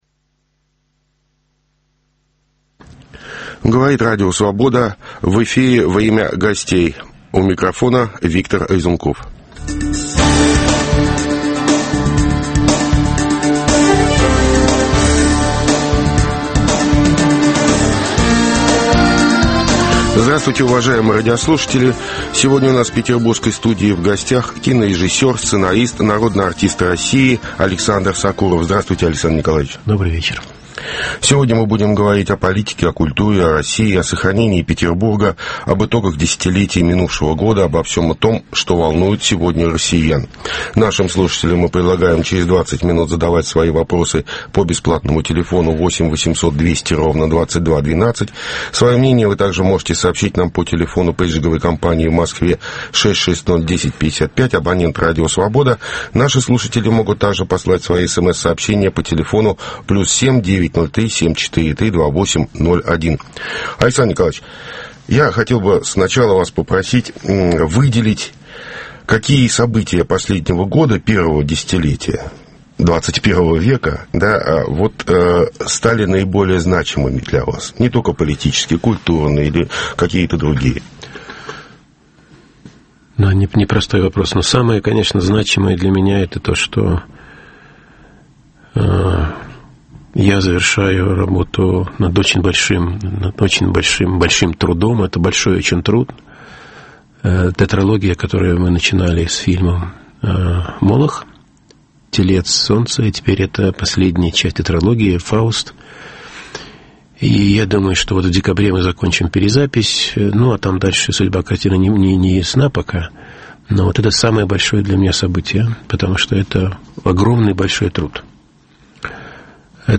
К вопросу о патриотизме: имеют ли право мастера культуры выступать с политическими заявлениями? Обсуждаем с кинорежиссером, сценаристом, заслуженным деятелем искусств РФ Александром Сокуровым.